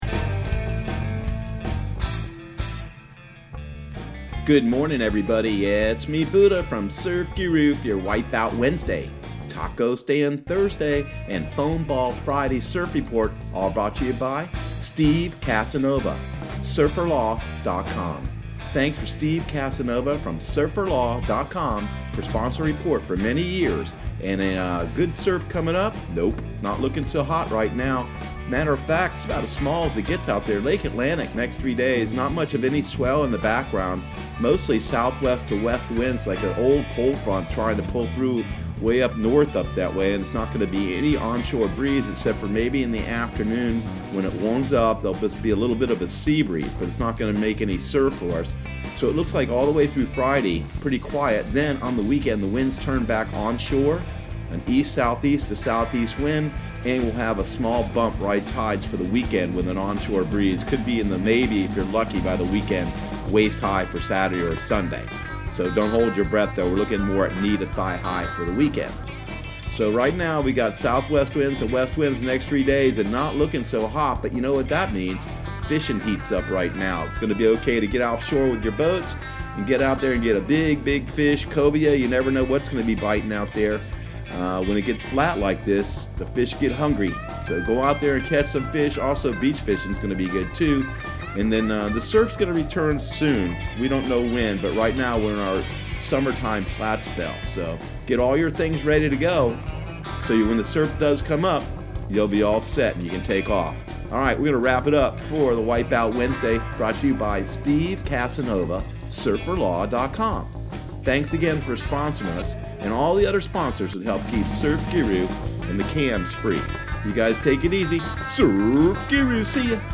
Surf Guru Surf Report and Forecast 06/19/2019 Audio surf report and surf forecast on June 19 for Central Florida and the Southeast.